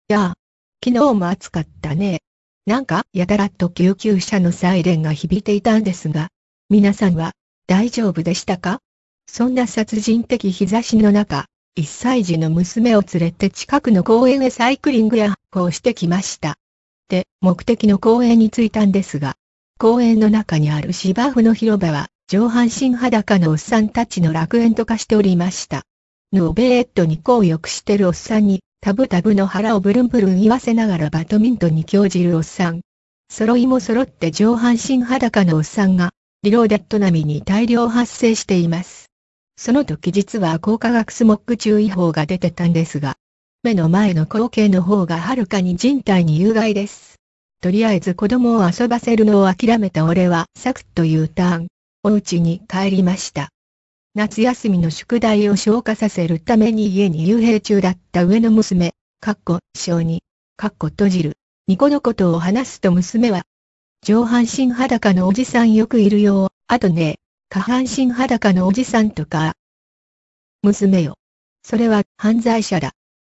TEXTを打つとその通りしゃべってくれるというありがちなソフトなんですが
その上語尾に「？」などを付けるとちゃんと疑問形イントネーションにしてくれたりするのだ。